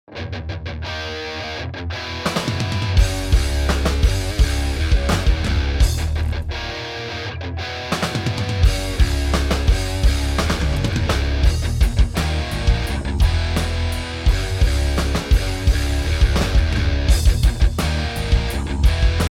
pour le gros rock et le métal c'est généralement bénéfique
si on n'avait pas doublé les grattes, voici comment ça sonnerait